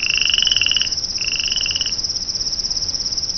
nitepond.wav